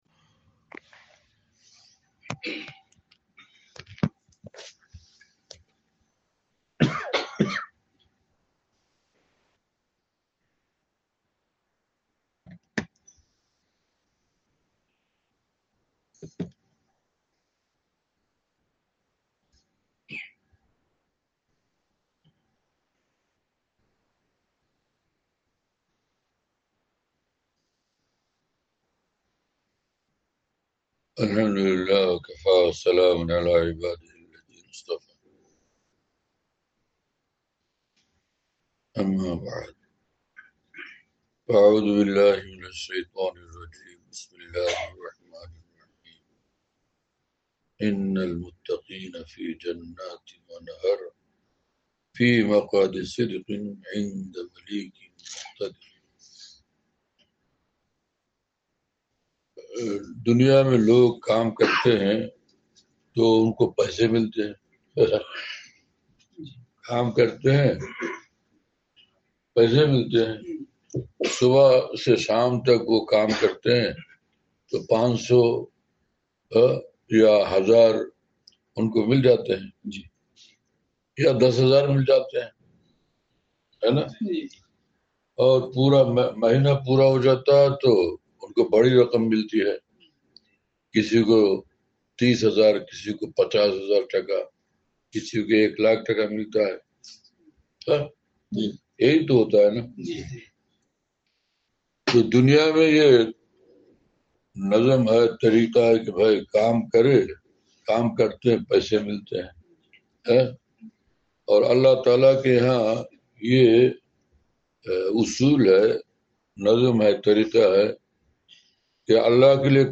07 فروری 2026ء بعد طواف:عظیم الشان معافی !مکہ ٹاور
مجلس